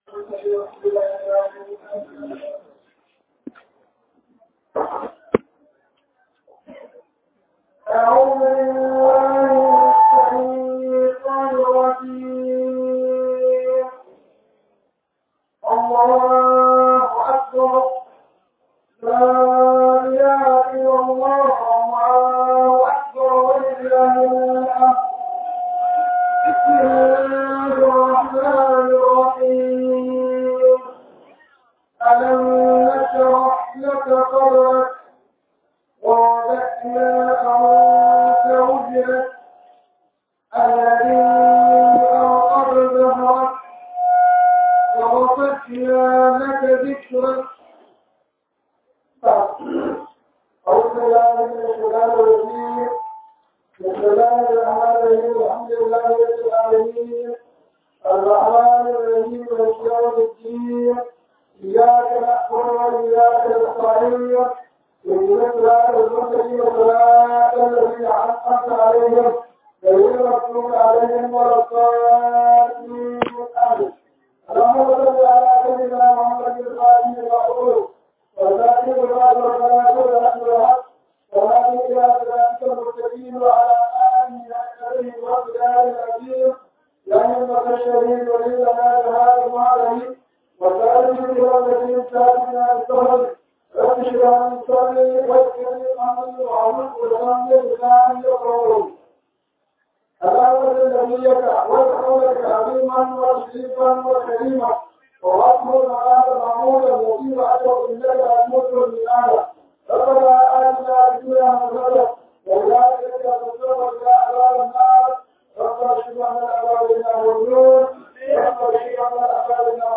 RAMADAN TAFSIR 2024